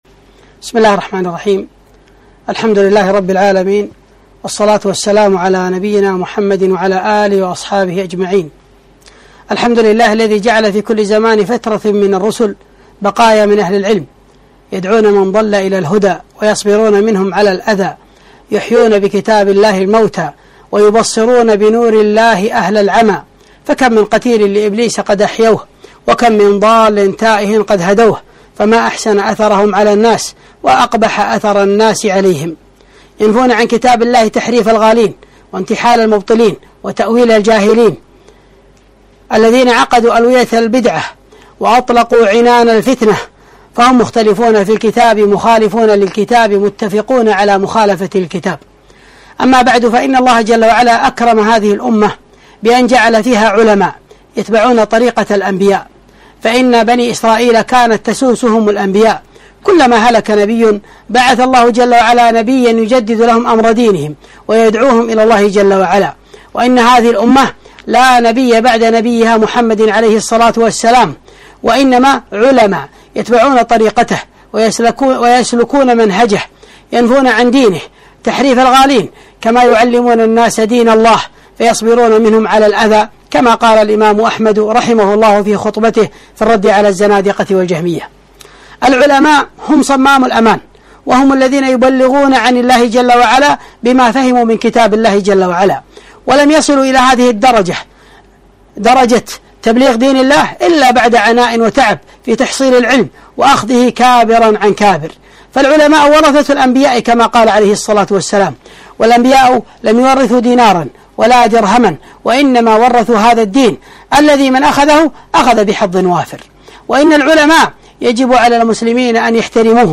محاضرة - الشيخ ربيع كما عرفته